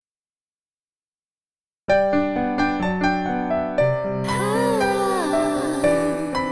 / Pop / Rock